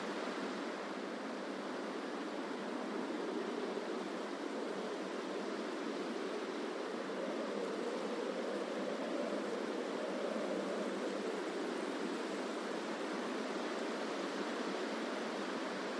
desert_ambience_loop_01.wav.mp3